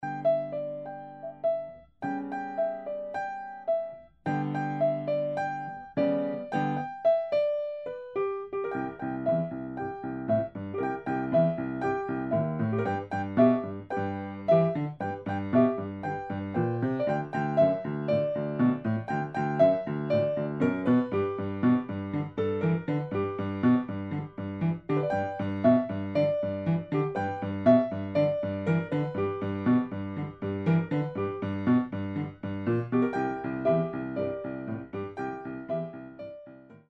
Style: Boogie Woogie Piano